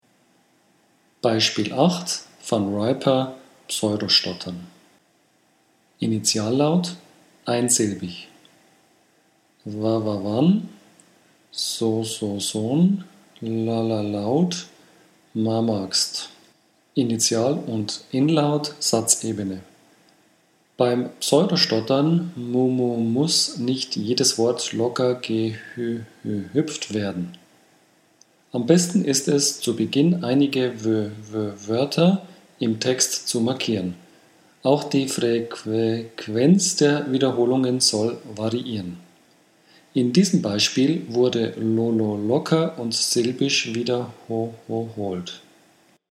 Sprechtechniken in der Stottertherapie. Übersicht über gängige Sprechtechniken mit Audio-Beispielen
Soundbeispiel 8 (Van Riper: Pseudo-Stottern)
08_Van_Riper_Pseudo-Stottern.mp3